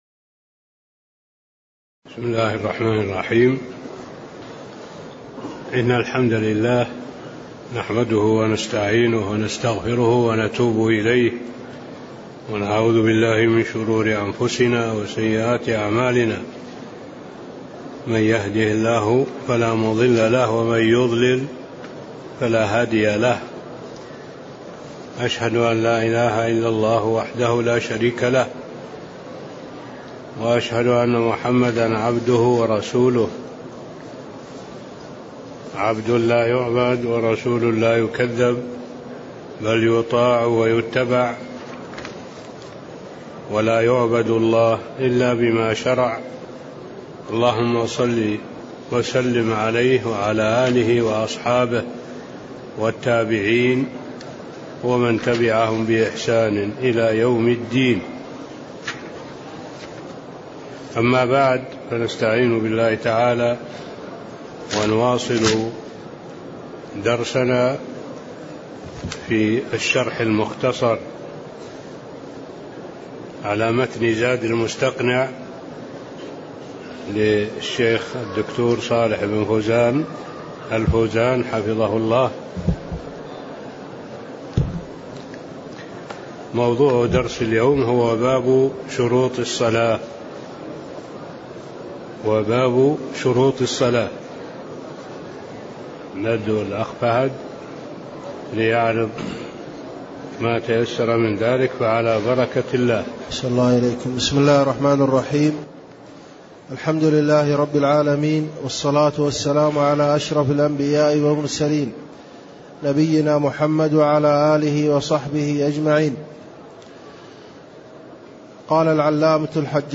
تاريخ النشر ٢١ ربيع الثاني ١٤٣٤ هـ المكان: المسجد النبوي الشيخ: معالي الشيخ الدكتور صالح بن عبد الله العبود معالي الشيخ الدكتور صالح بن عبد الله العبود باب شروط الصلاة (02)